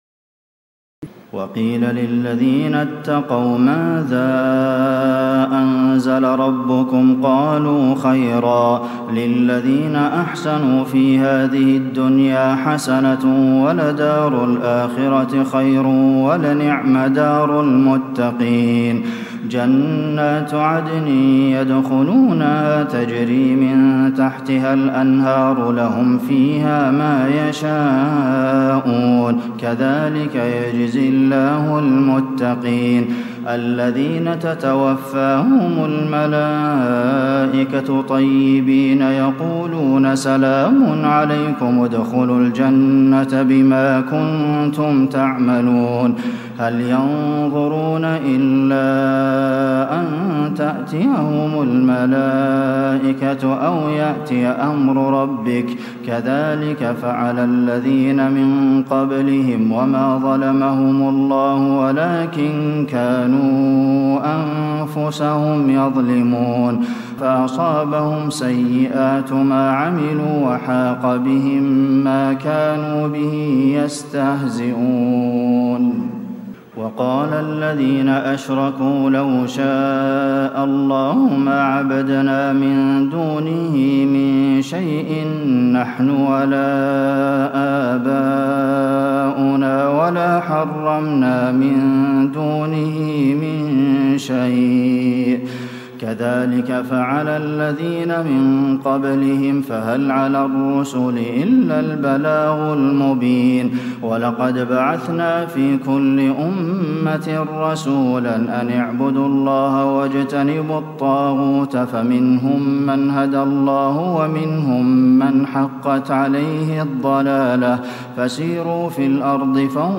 تراويح الليلة الثالثة عشر رمضان 1436هـ من سورة النحل (30-110) Taraweeh 13 st night Ramadan 1436H from Surah An-Nahl > تراويح الحرم النبوي عام 1436 🕌 > التراويح - تلاوات الحرمين